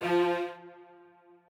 strings9_17.ogg